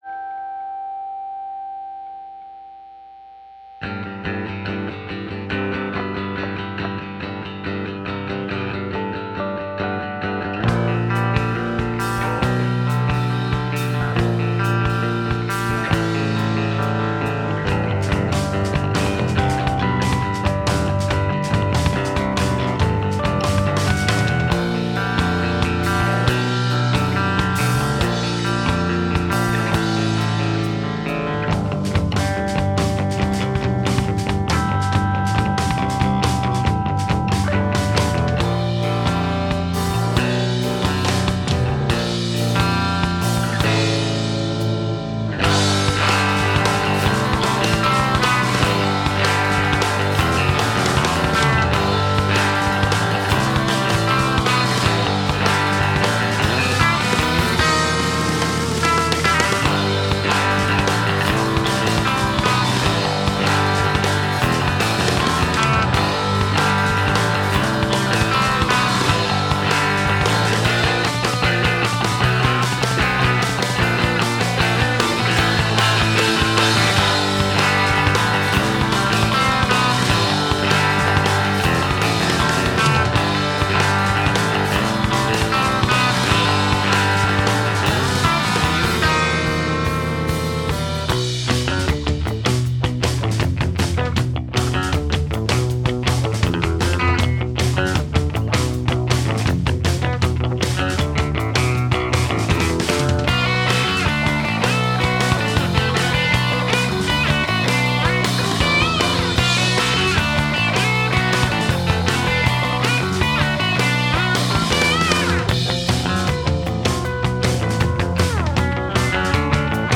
Instrumental punk rock
punk rock See all items with this value